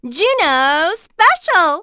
Juno-Vox_Skill3.wav